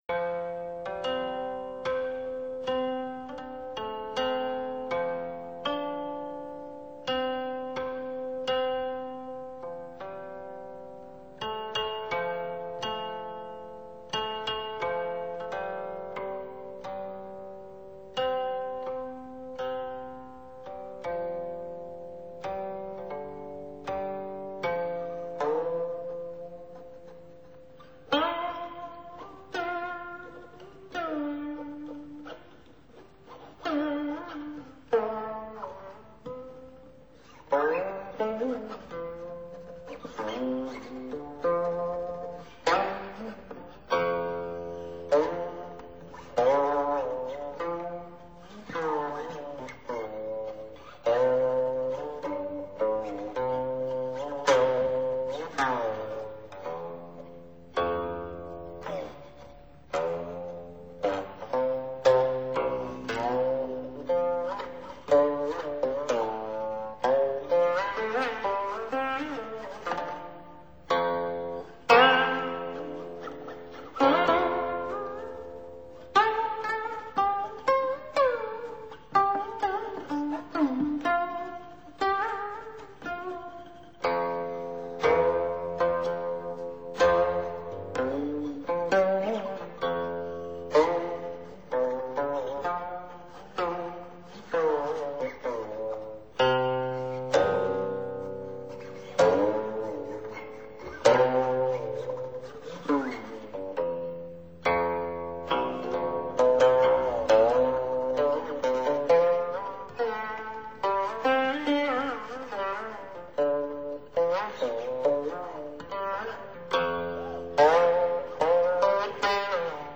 这套录音在50年代。